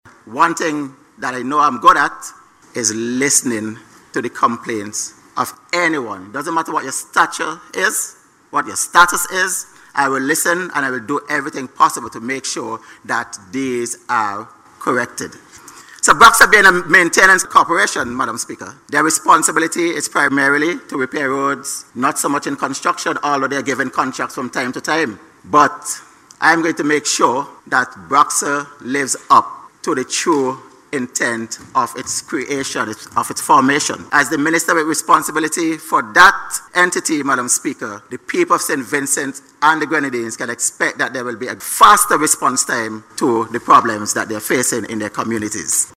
Speaking during his contribution to the Budget Estimates Debate last Thursday, Minister Stephenson said the public can be confident that he will work to ensure their issues are addressed.